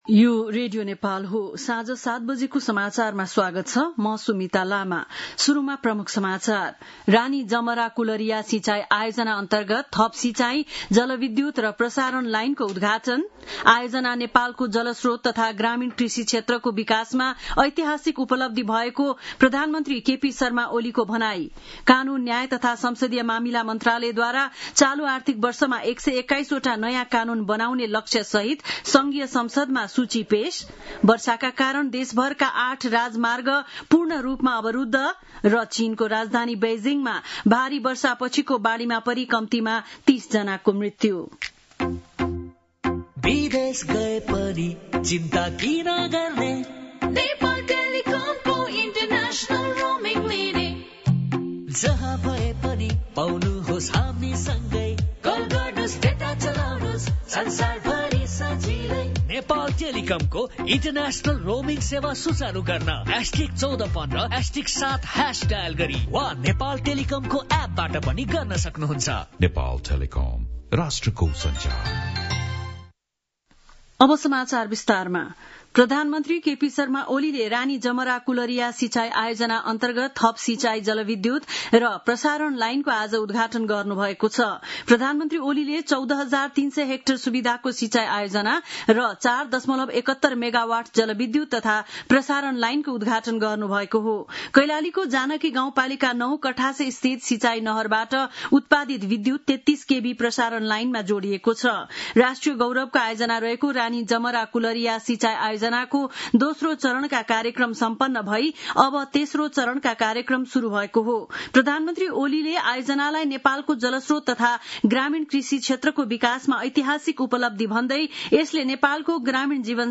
बेलुकी ७ बजेको नेपाली समाचार : १३ साउन , २०८२